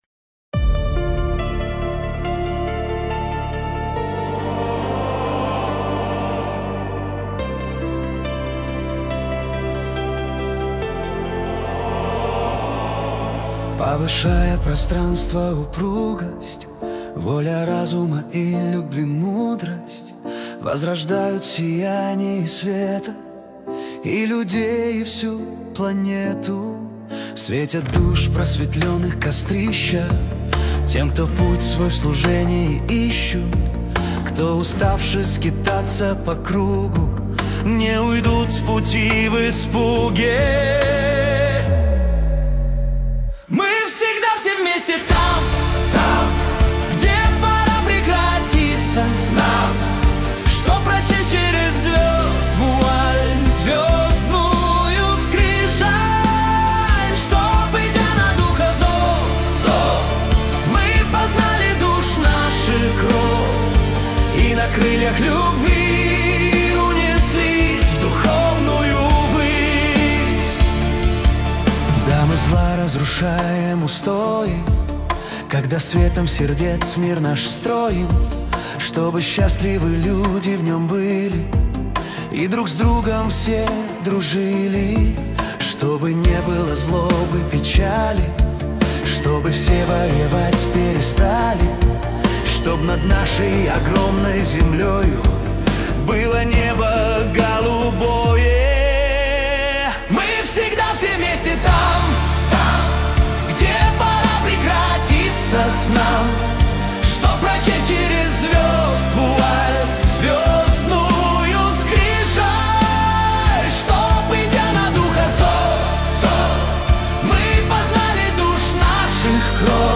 кавер-версия на мотив песни
Для Медитаций